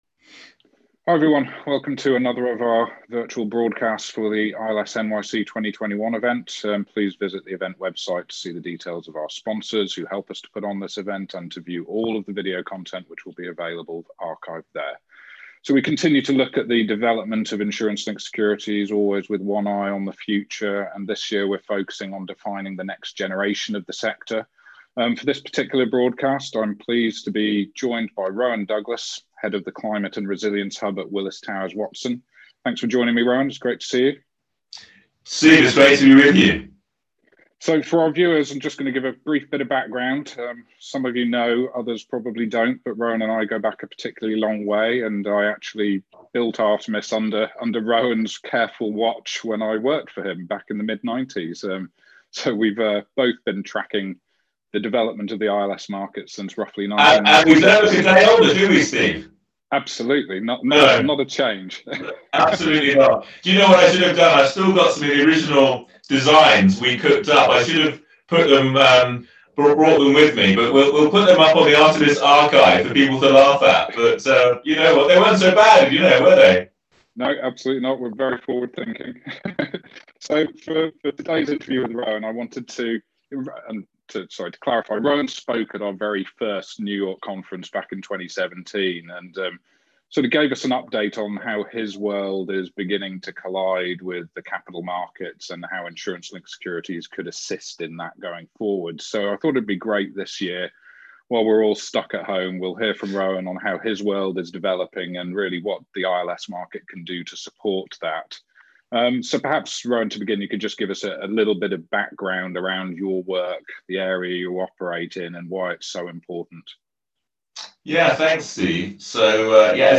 The conversation also discussed the important topic of credit and how embedding climate into broader global financial credit decisions could be a tipping point for the need for climate risk transfer capital.